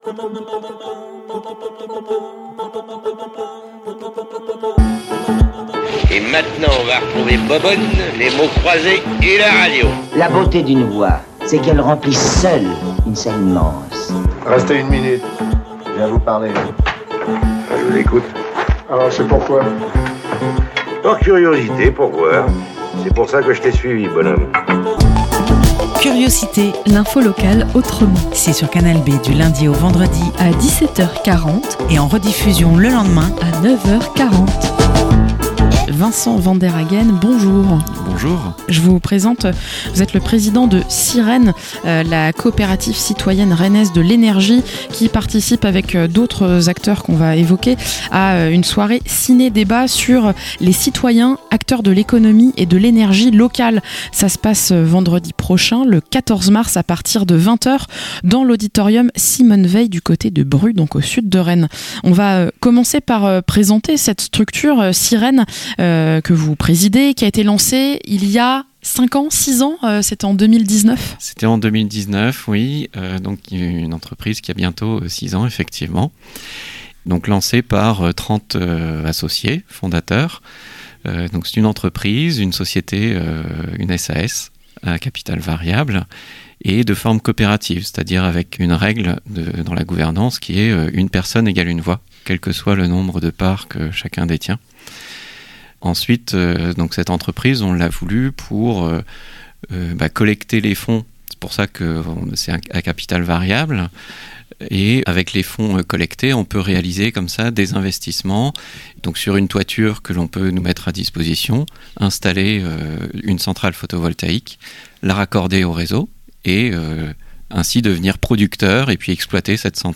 - Interview